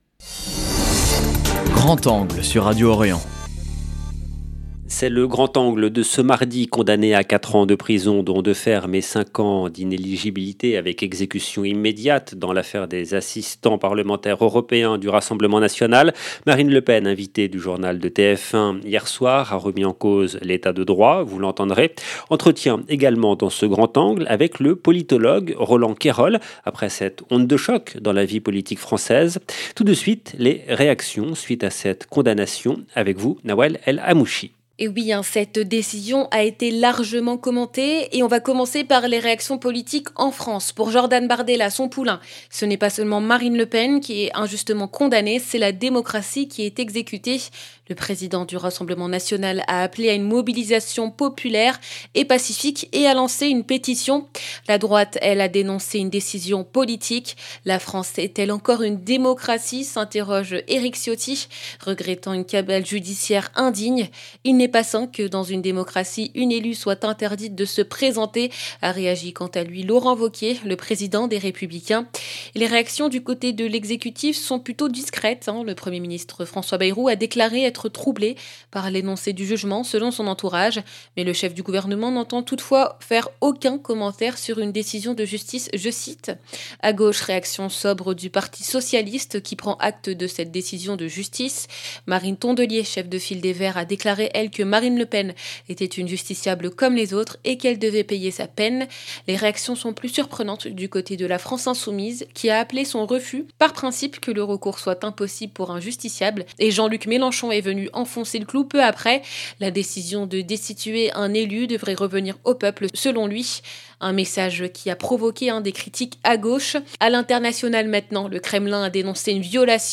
Condamnée à quatre ans de prison, dont deux ferme et cinq ans d’inéligibilité avec exécution immédiate dans l’affaire des assistants européens du RN, Marine Le Pen, invitée du journal de TF1 hier soir, a remis en cause l'état de droit, vous l’entendrez.